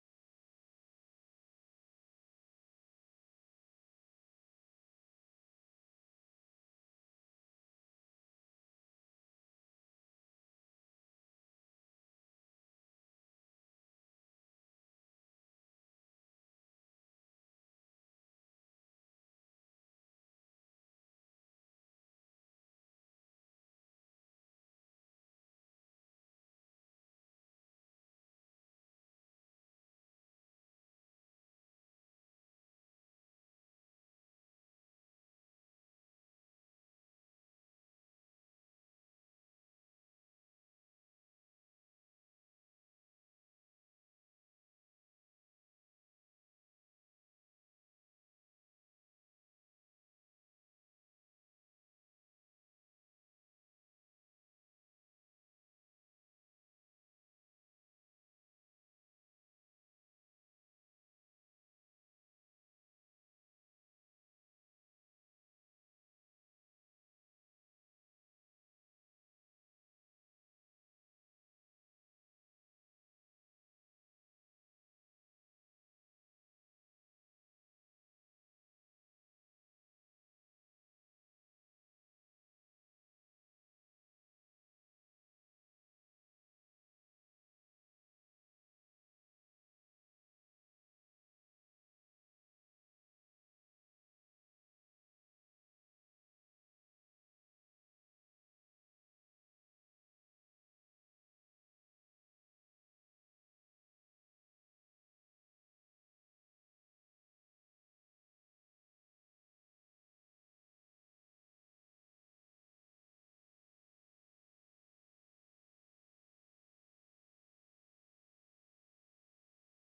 Passage: 1 Corinthians 11:1, 17-30 Service Type: Sunday AM